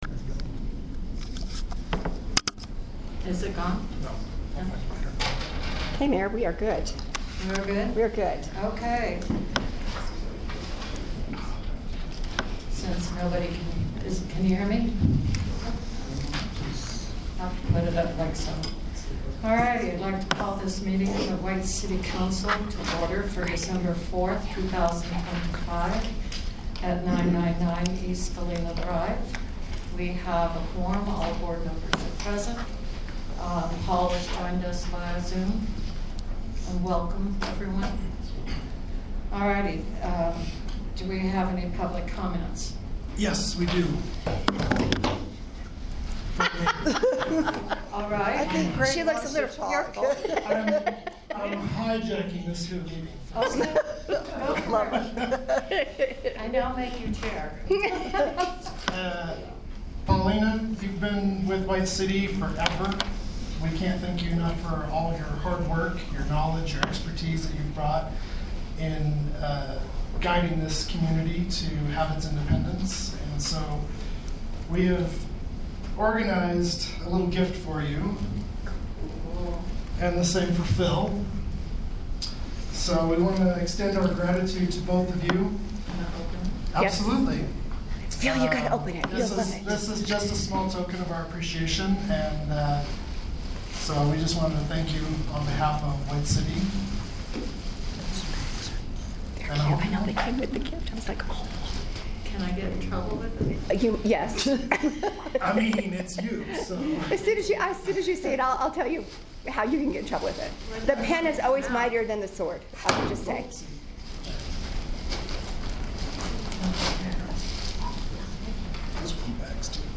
Council Meeting